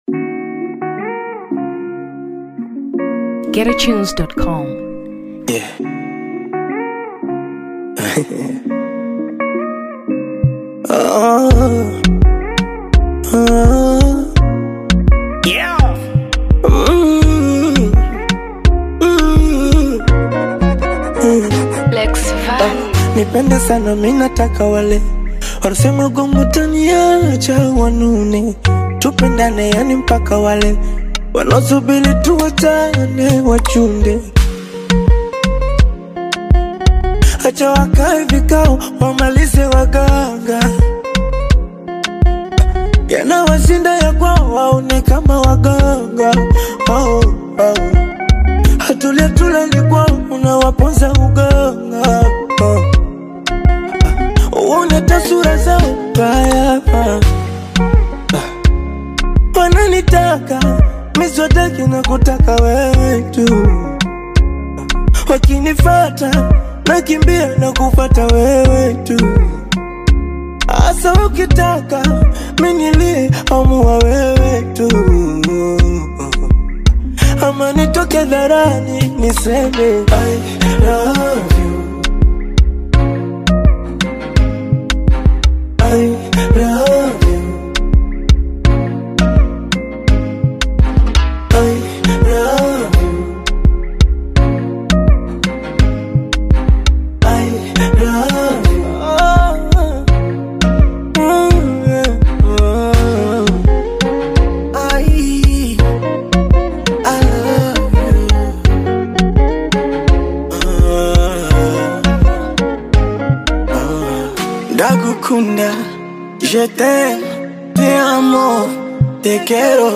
Afrobeats 2023 Tanzania